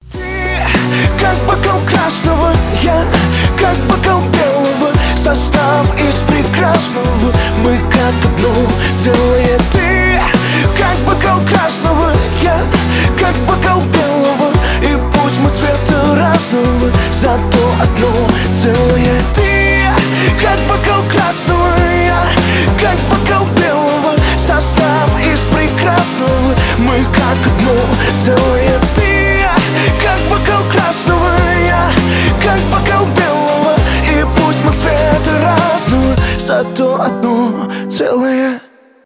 поп зажигательные